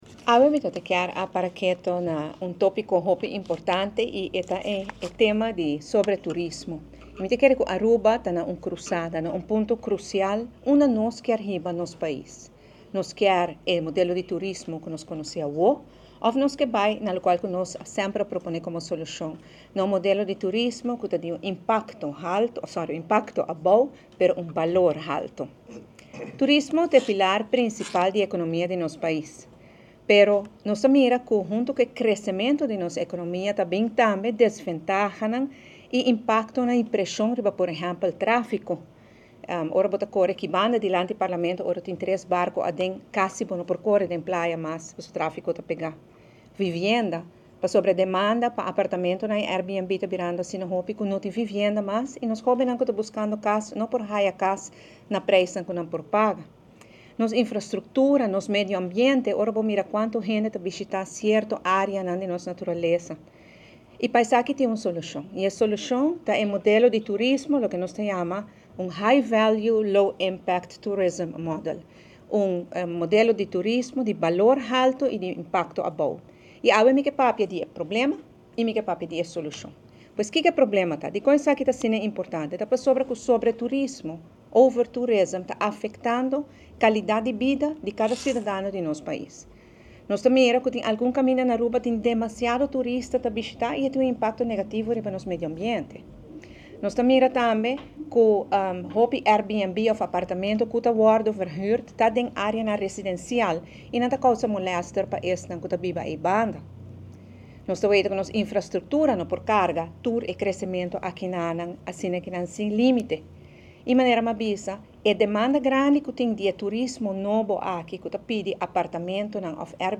AUDIO: Evelyn Conferencia 13 April 2026 Item 1
ORANJESTAD — Den conferencia di prensa di MEP, lider di fraccion Evelyn Wever-Croes, a haci un pregunta importante: con nos kier sigui cu turismo?